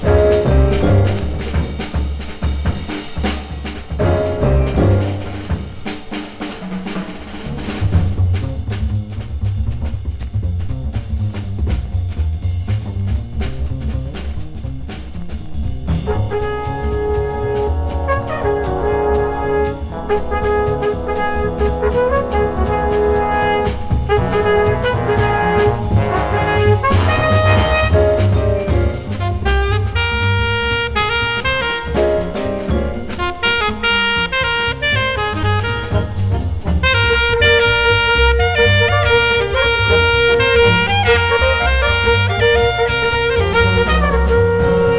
クールとモーダルと神秘の三拍子を揃った、様々な角度から音楽を楽しみ感じさせる1枚。
Jazz, Classical, Modal　USA　12inchレコード　33rpm　Mono